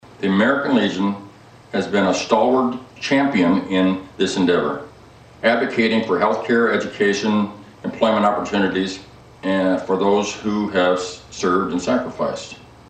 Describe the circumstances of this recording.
The Coldwater American Legion Post #52, which was founded in 1919, held their annual Veterans Day ceremony which was followed by an open house for the community.